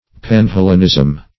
Search Result for " panhellenism" : The Collaborative International Dictionary of English v.0.48: Panhellenism \Pan*hel"len*ism\, n. A scheme to unite all the Greeks in one political body.
panhellenism.mp3